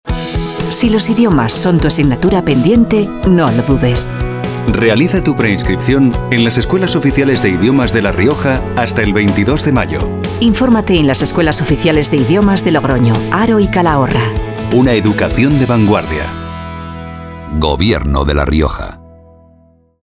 Elementos de Campaña Cuña radiofónica Cuña 20".